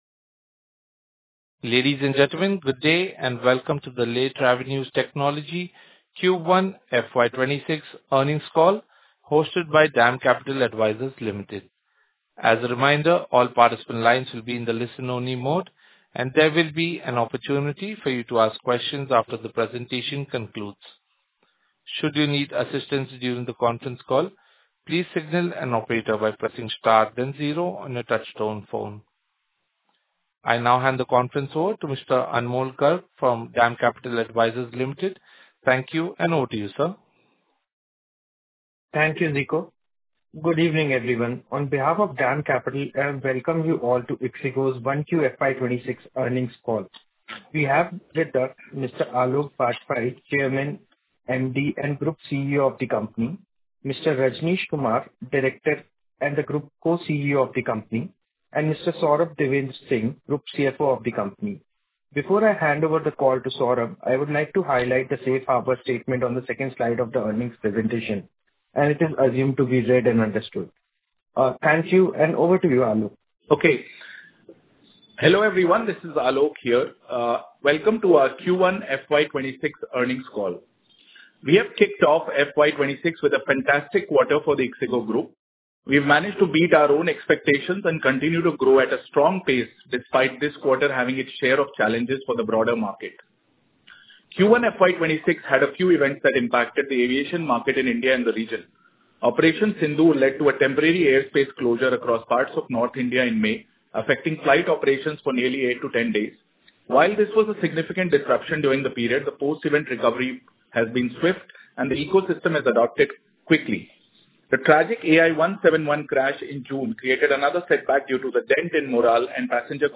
Q1 FY26 - Earnings Call Recording.mp3